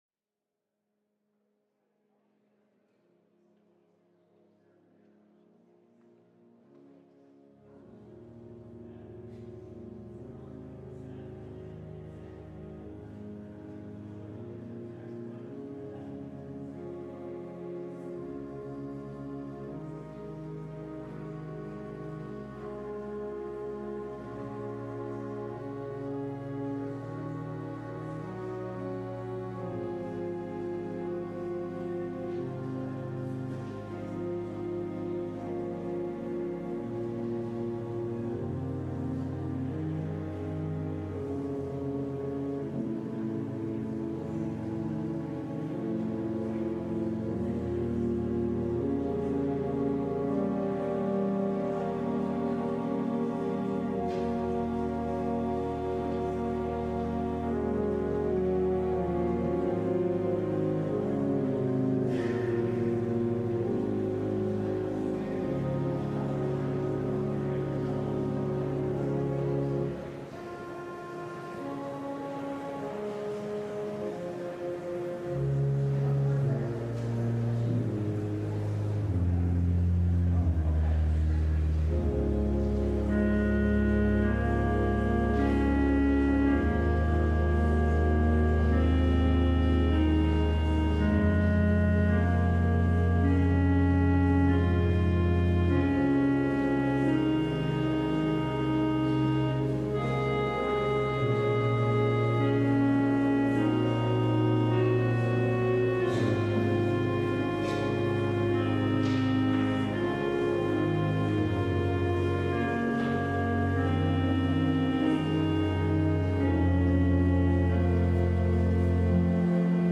LIVE Morning Worship Service - Kindness